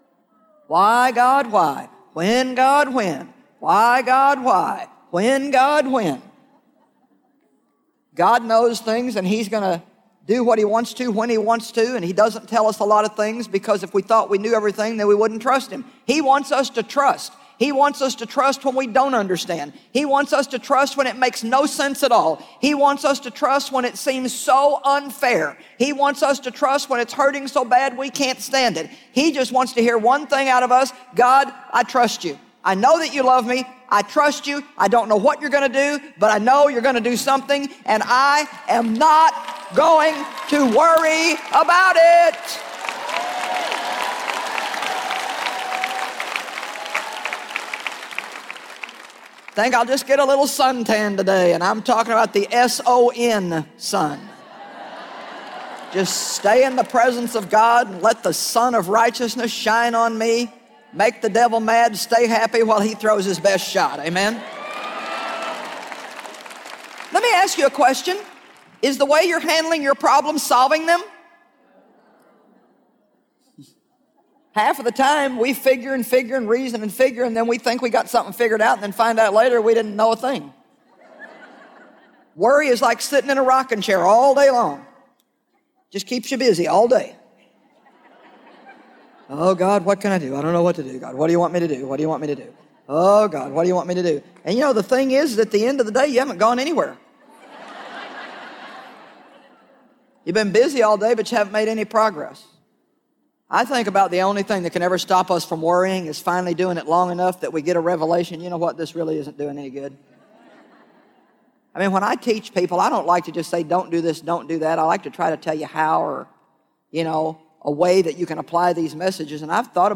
The Cure for Stress Audiobook
Narrator
Joyce Meyer
3.72 Hrs. – Unabridged